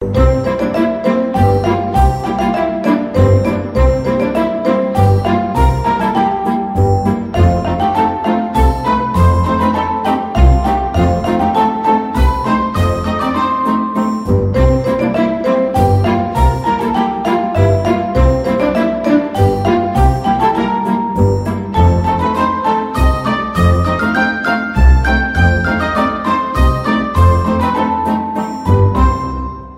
Catégorie Alarme/Reveil